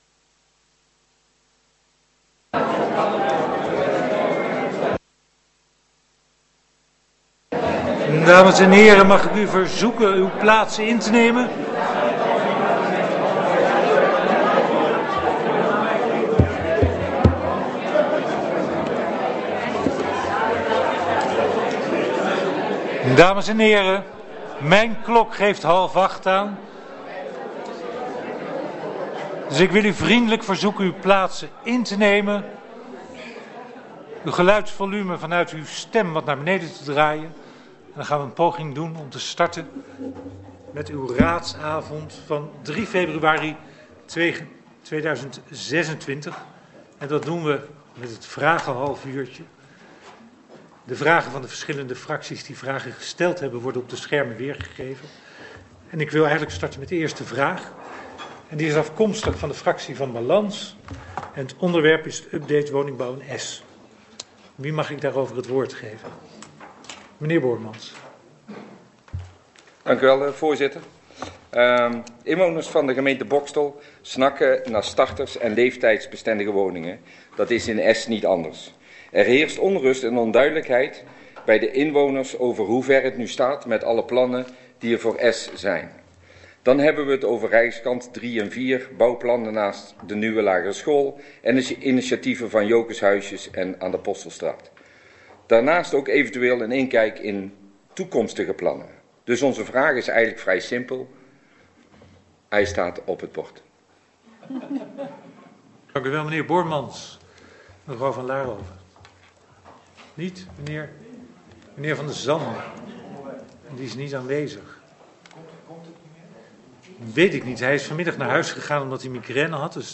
Agenda boxtel - Raadsvergadering Boxtel dinsdag 3 februari 2026 19:30 - 23:00 - iBabs Publieksportaal
Locatie Raadzaal Boxtel Voorzitter Ronald van Meygaarden Toelichting Wilt u de raadsvergadering als toehoorder volgen?
Agenda documenten Geluidsopname raadsvergadering 3 februari 2026 79 MB